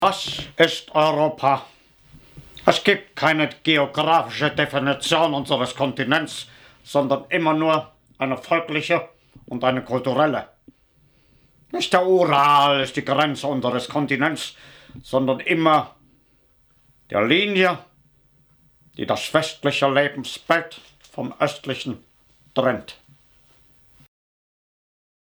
Geschichten, Kabarettszenen, Parodien und Minihörspiele.
Adolf Hitler - Bewerb ung für eine Filmrolle, mit einem Originaltext, des Diktators.